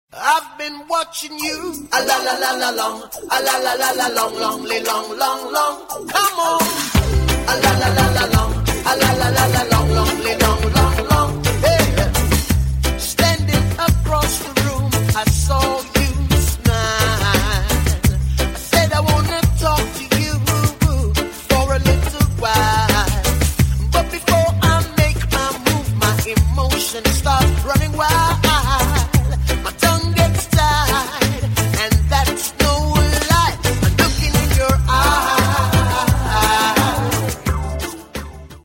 Регги Рингтоны